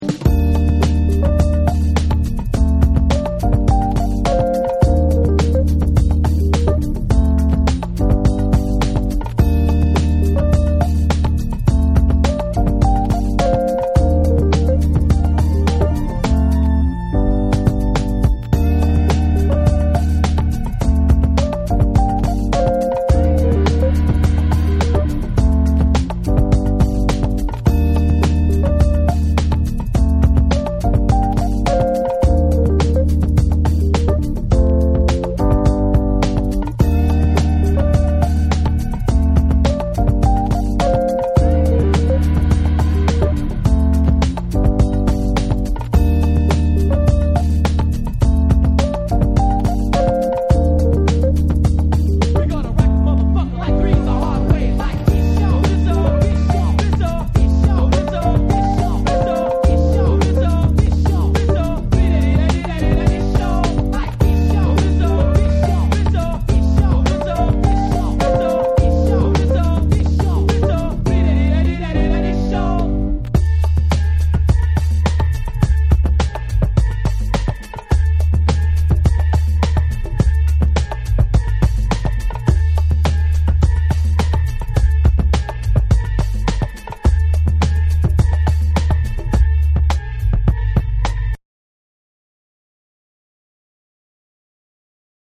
ジャズを中心としたロウな質感のサンプルとタイトなビート、トラックを引き立てるフロウが一体となり展開。
BREAKBEATS / HIP HOP